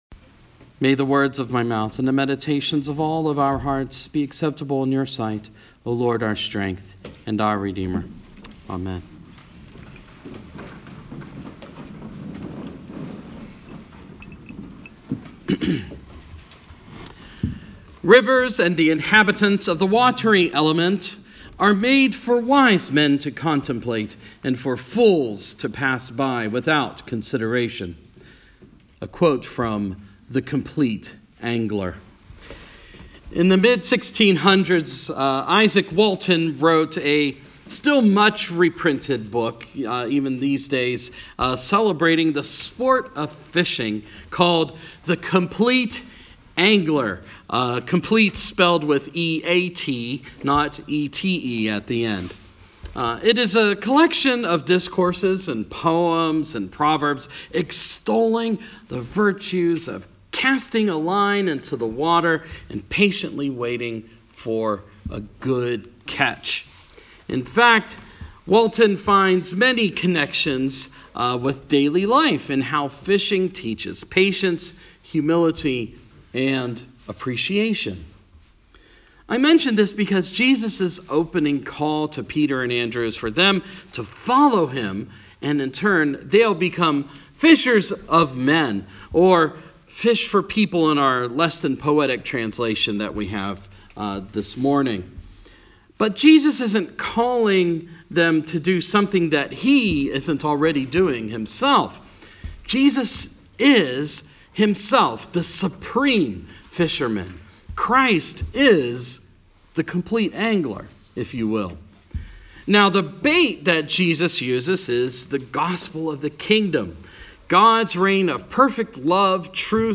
Sermon – 3rd Sunday after Epiphany